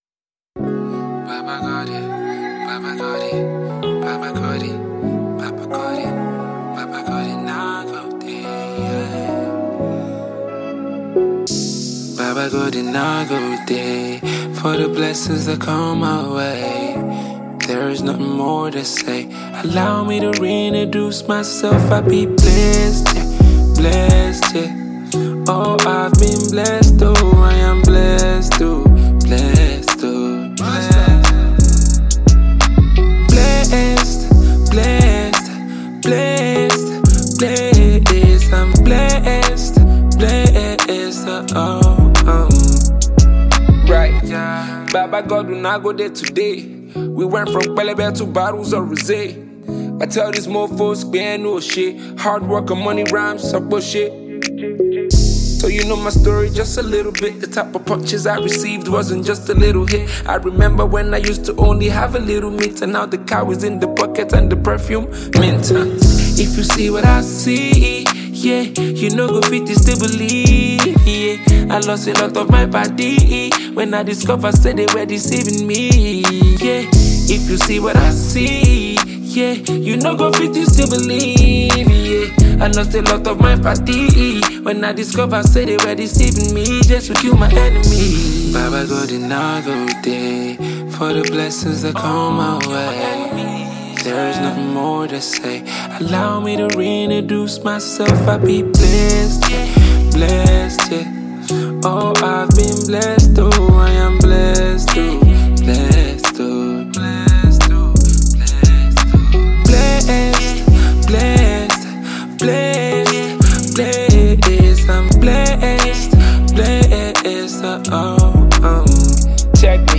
Nigerian rap artist